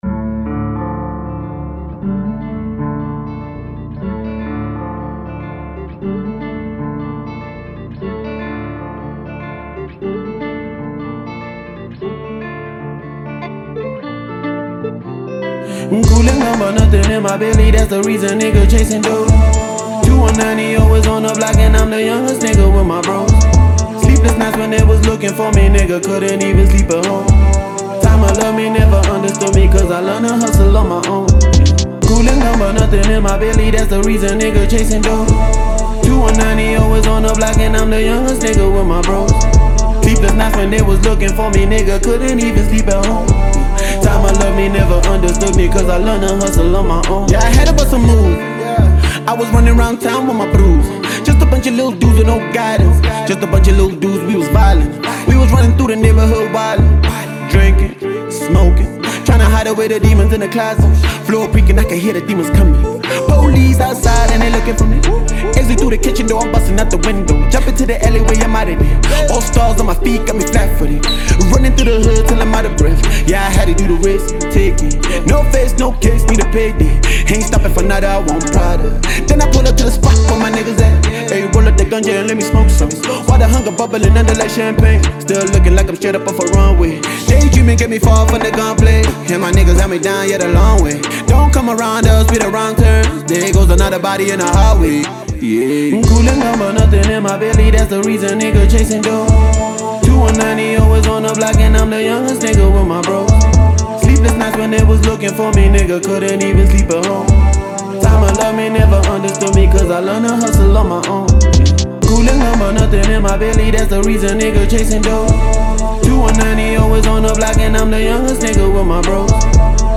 SONG KIND: South African Music.